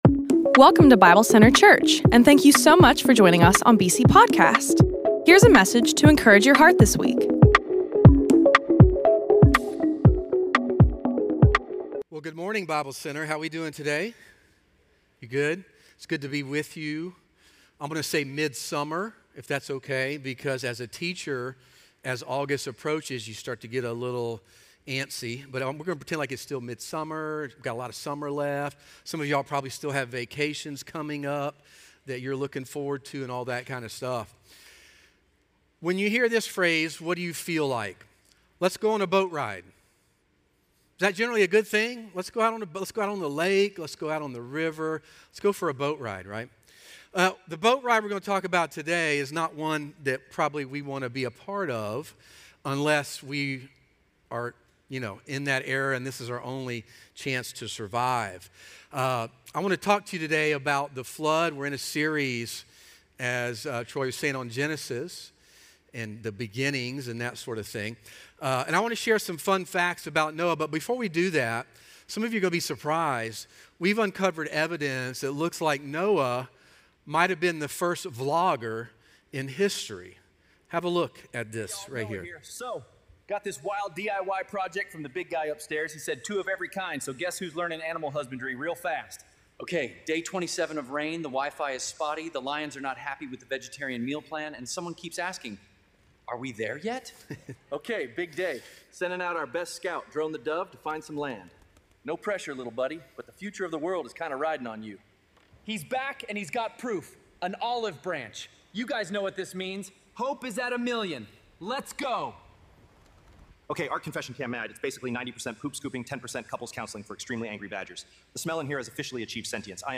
Stand-Alone Sermons 2022 | Bible Center Church